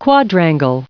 quadrangle_en-us_recite_stardict.mp3